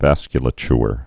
(văskyə-lə-chr, -chər)